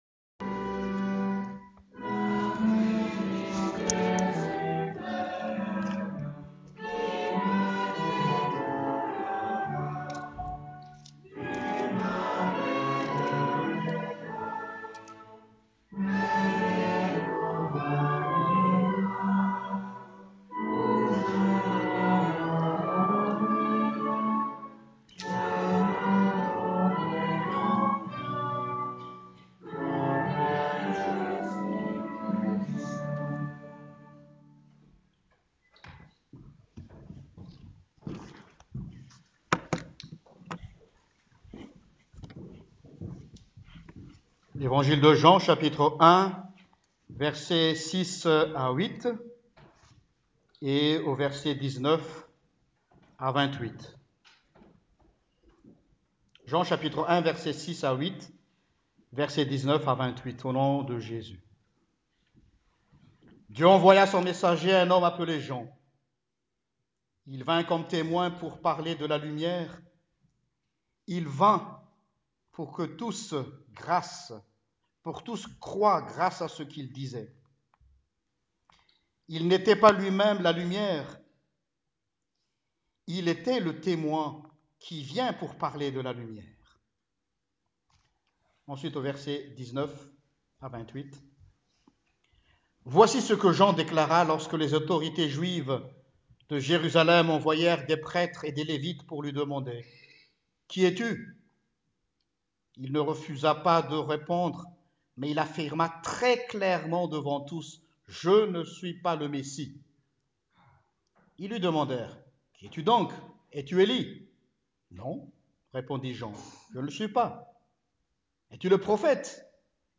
Prédication du 17 Décembre 2017: MERCI D’EXISTER
predication-17-decembre.m4a